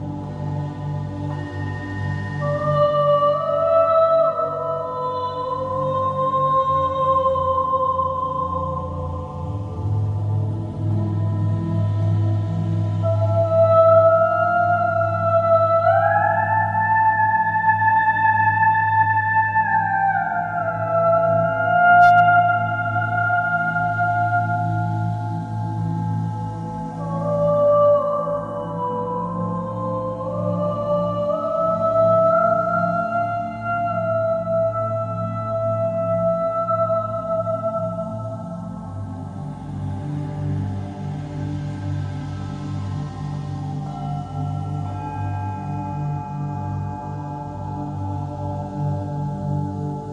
Bols chantants et Voix                    Durée 10:24